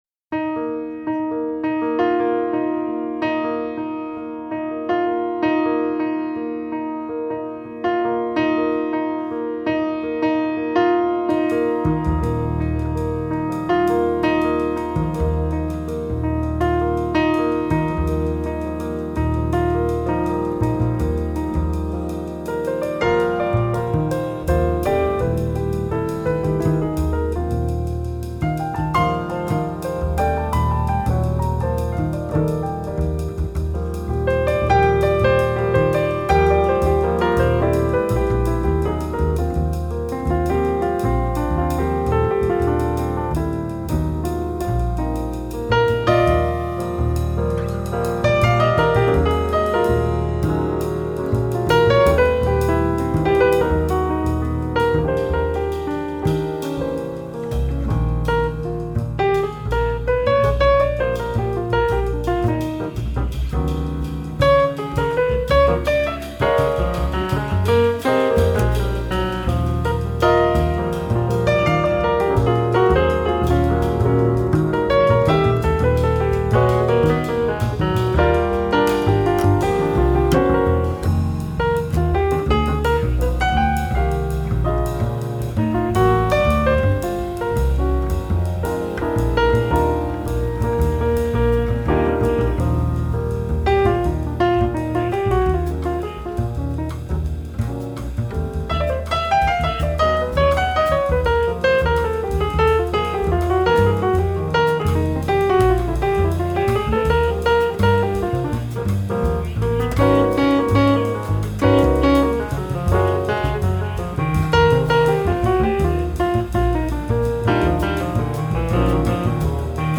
Klavier
Bass
Schlagzeug